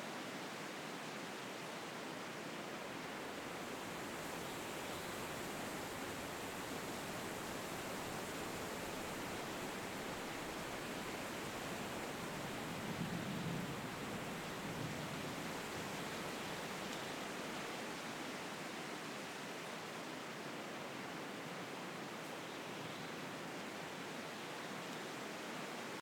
leaves.ogg